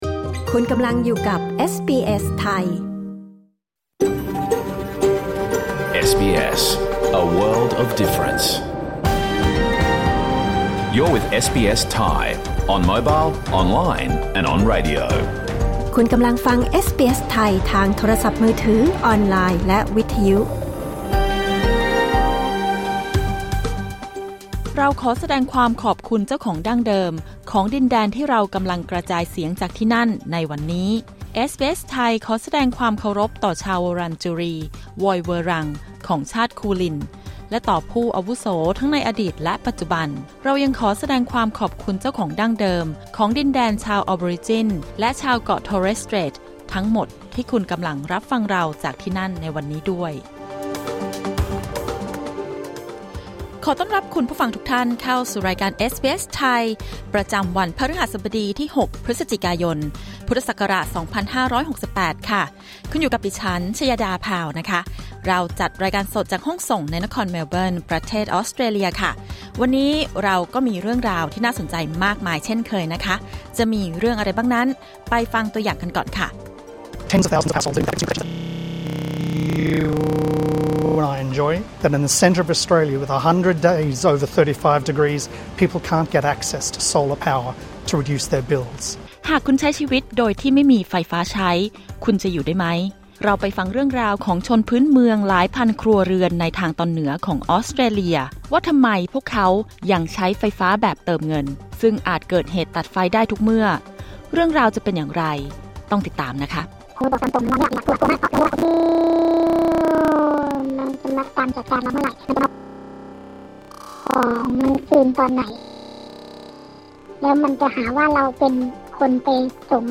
รายการสด 6 พฤศจิกายน 2568